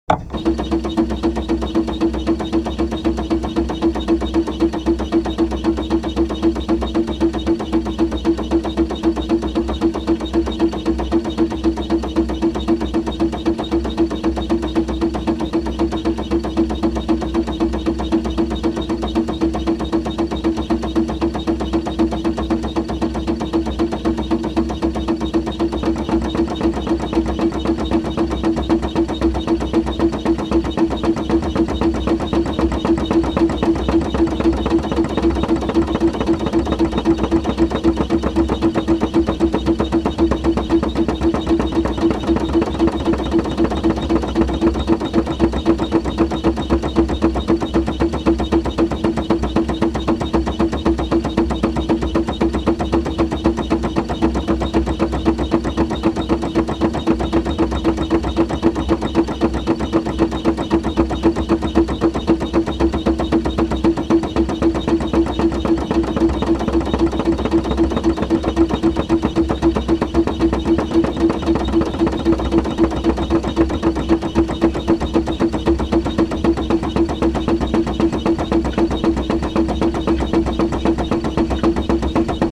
Field Recording Series